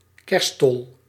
Kerststol (Dutch: [ˈkɛrstɔl]
Nl-kerststol.ogg.mp3